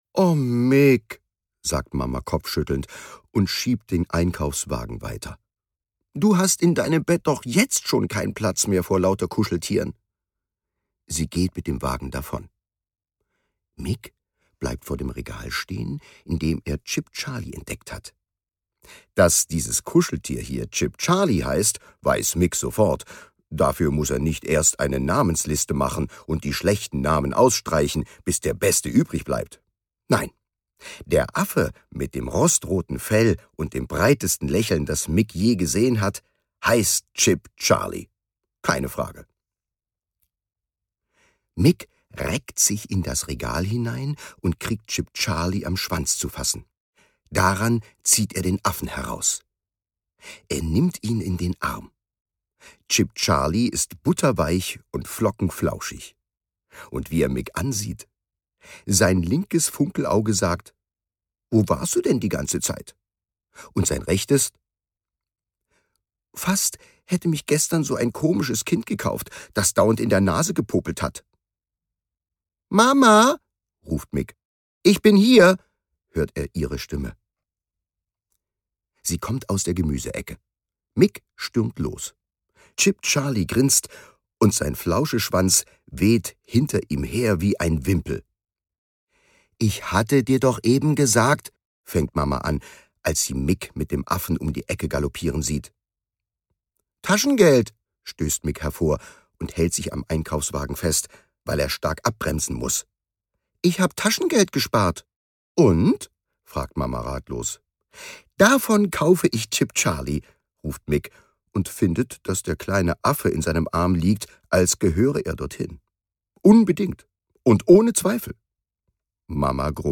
Chip Charlie und die Kuschelbande Rusalka Reh (Autor) Thomas Nicolai (Sprecher) Audio-CD 2022 | 1.